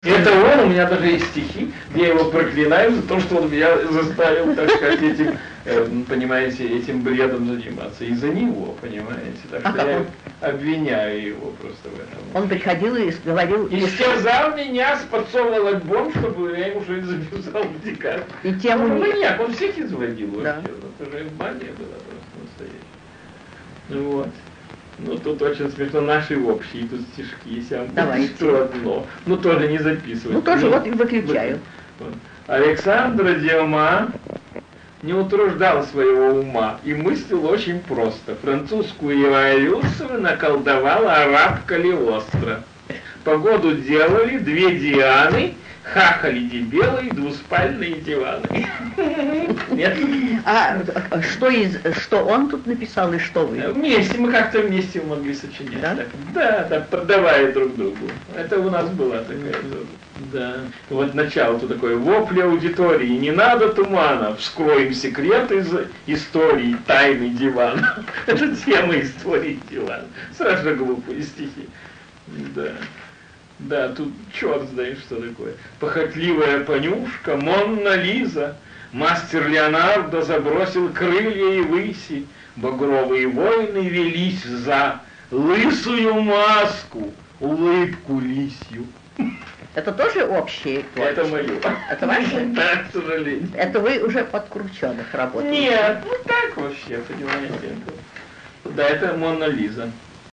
Николай Харджиев читает собственные стихи и стихи, написанные совместно с Алексеем Крученых.